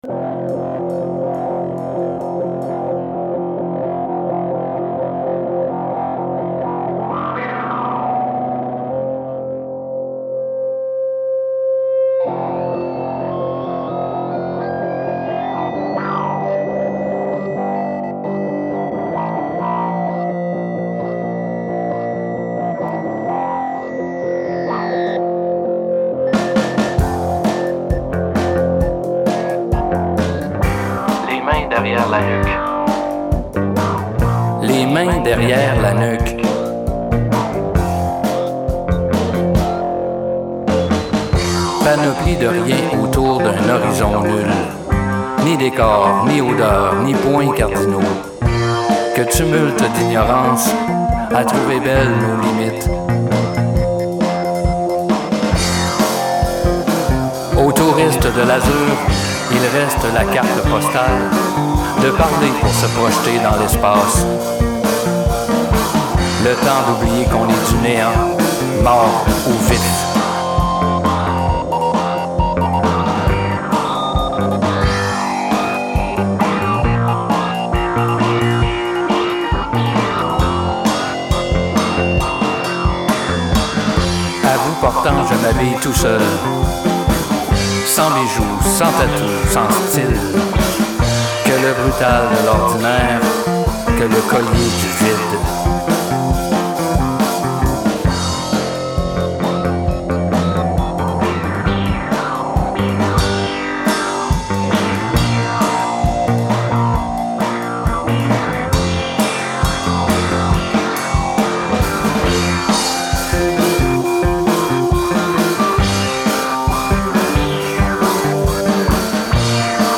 Une poésie vivante
Slam, musique et vidéo
Texte, voix, performances et créations audio :
Compositions et instrumentations :